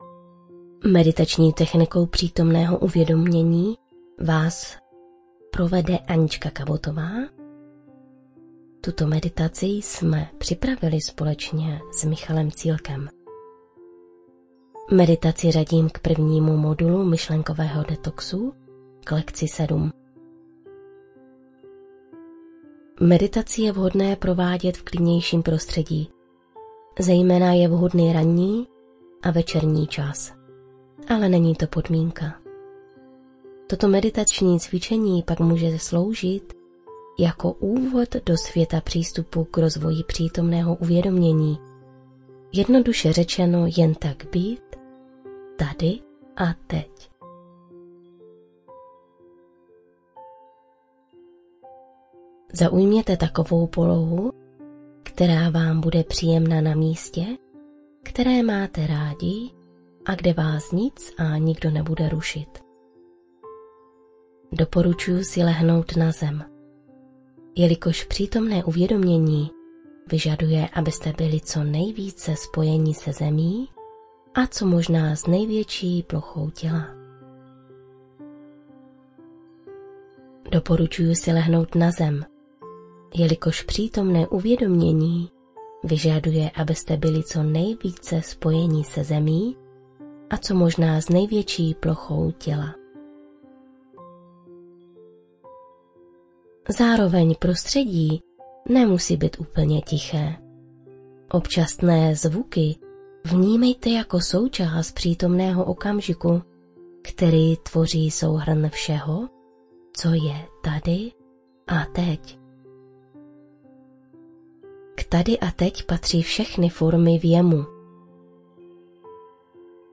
Meditace z živého vysílání ze dne 20. ZÁŘÍ 2018 – PŘÍTOMNÝ OKAMŽIK
2.-MEDITACE-PRITOMNEHO-UVEDOMENI-hudba2.mp3